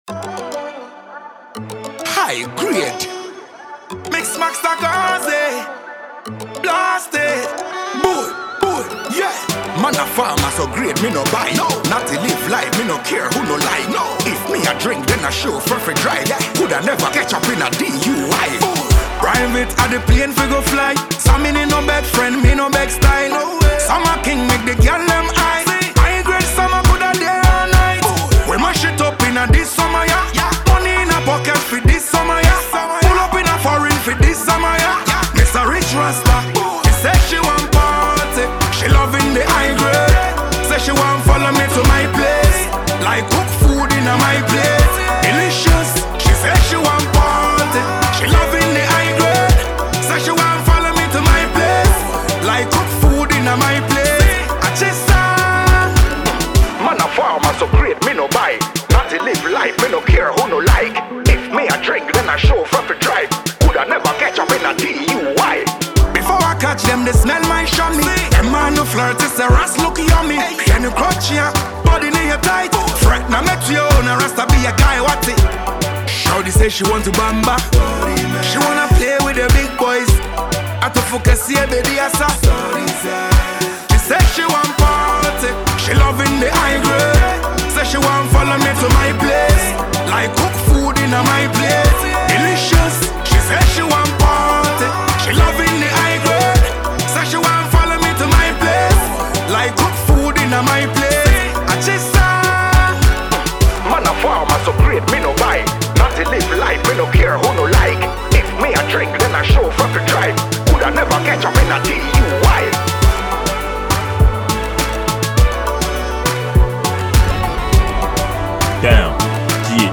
Ghanaian dancehall reggae afrobeat and highlife musician